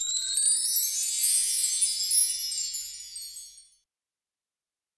PERC.28.NEPT.wav